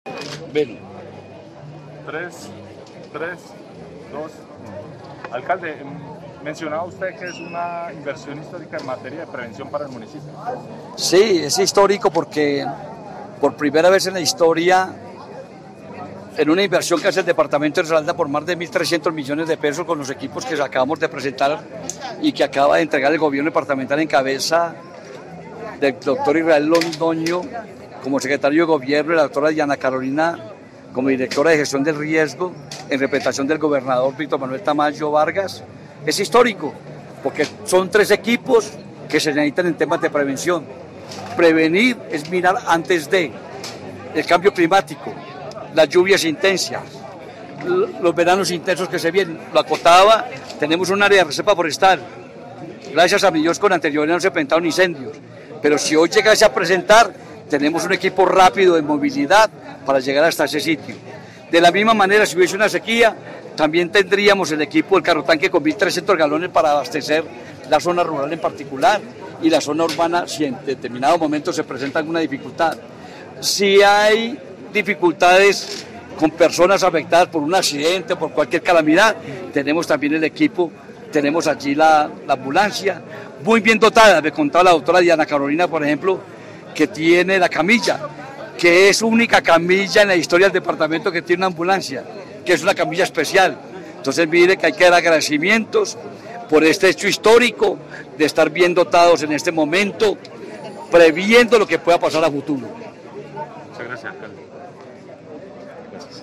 Escuchar Audio: Alberto Rivera, alcalde de Marsella.